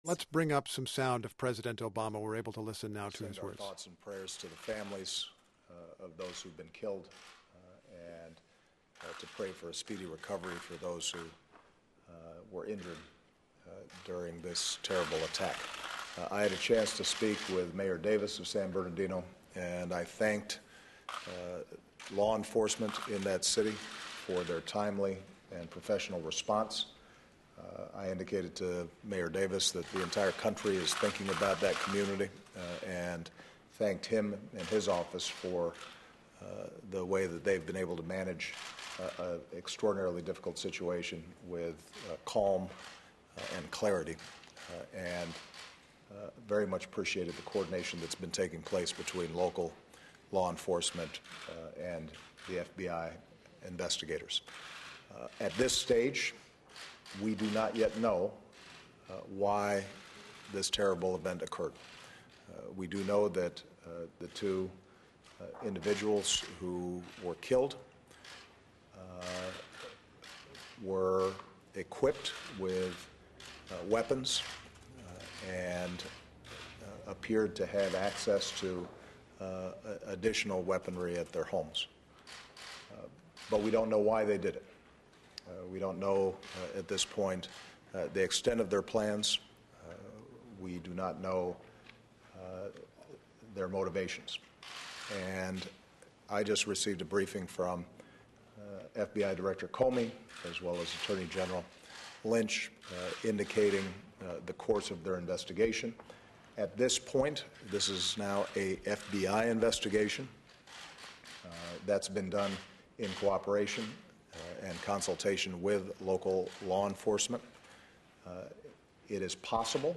In a statement from the Oval Office, the president also said the investigation into Wednesday's shooting in San Bernardino, Calif., has been handed over to the FBI.
President Obama, joined by Vice President Joe Biden, delivers a statement at the White House about gun violence.